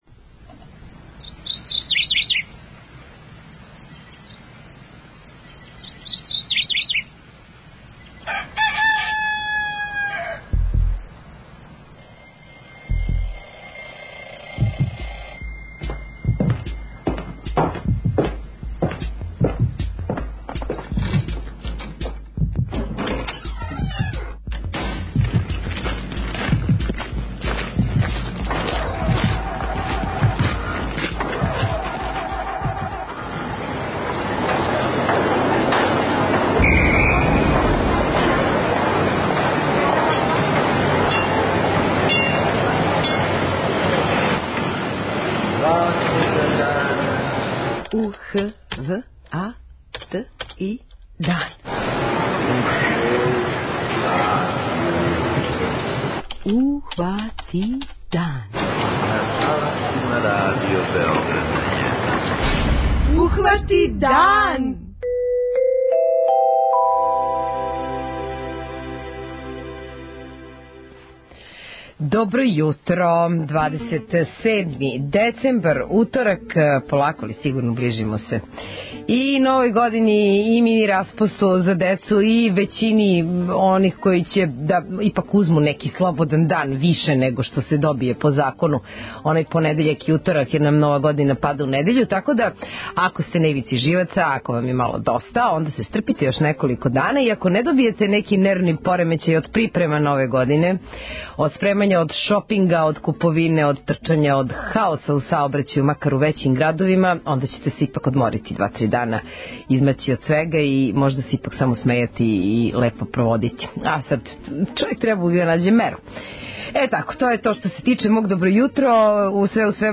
преузми : 21.57 MB Ухвати дан Autor: Група аутора Јутарњи програм Радио Београда 1!